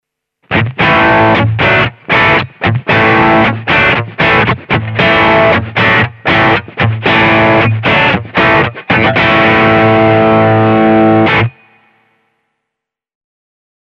The singlecoil pickup
These slim and tall pickups tend to have a bright, cutting sound.
Telecaster drive
tele-drive.mp3